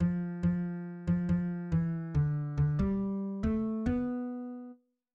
<< %{ \new Staff \with {instrumentName = "S A" shortInstrumentName = "S A"} << \set Staff.midiMinimumVolume = #0.3 \set Staff.midiMaximumVolume = #0.7 \set Score.currentBarNumber = #1 \bar "" \tempo 4=70 \time 4/4 \key bes \major \new Voice = "s1" \relative c '' { \voiceOne bes2 a bes a bes4 a c a bes2 a } \new Voice = "s2" \relative c '{ \voiceTwo ees2 d ees d ees4 d f d ees2 d } >> \new Lyrics \lyricsto "s1" {\set fontSize = #-2 s' brent s' brent und- zer shte- tl bre -nt de } %} %{ \new Staff \with {instrumentName = "A" shortInstrumentName = "A"} << \set Staff.midiMinimumVolume = #0.3 \set Staff.midiMaximumVolume = #0.5 \set Score.currentBarNumber = #1 \bar "" \tempo 4=70 \time 4/4 \key bes \major \new Voice = "a1" \fixed c ' { \voiceOne g1 g g g } \new Voice = "a2" \fixed c ' { \voiceTwo d1 d d d } >> \new Lyrics \lyricsto "a1" {\set fontSize = #-2 } \repeat volta 2 %} %{ \new Staff \with {instrumentName = "T" shortInstrumentName = "T"} << \set Staff.midiMinimumVolume = #0.3 \set Staff.midiMaximumVolume = #0.5 \set Score.currentBarNumber = #1 \bar "" \tempo 4=70 \time 4/4 \key bes \major \new Voice = "t1" \fixed c ' { \voiceOne g1 g g g } \new Voice = "t2" \fixed c ' { \voiceTwo d1 d d d } >> \new Lyrics \lyricsto "t1" {\set fontSize = #-2 } \repeat volta 2 %} \new Staff \with {midiInstrument = #"acoustic bass" instrumentName = "B" shortInstrumentName = "B"} << \set Staff.midiMinimumVolume = #14.7 \set Staff.midiMaximumVolume = #15.9 \set Score.currentBarNumber = #1 \bar "" \tempo 4=70 \time 6/8 \key c \major \clef bass \new Voice = "b1" \relative c { \voiceOne f8 f8. f16 f8 e d d16 g8. a8 b4 r8 } { \new Voice = "b2" \relative c { \voiceOne \stemDown } } >> \new Lyrics \lyricsto "b1" {\set fontSize = #-2 Biz s’gist zikh in ey- bi- kn Ni- gn a- rayn } \new Lyrics \lyricsto "b1" {\set fontSize = #-2 } >> \midi{}